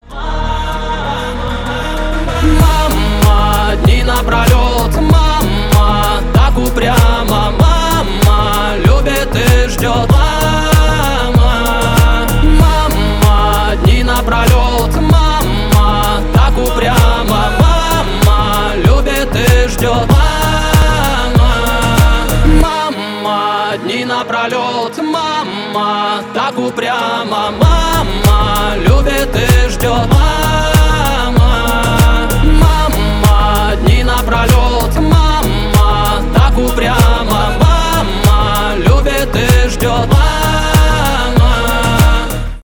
танцевальные , поп , ремиксы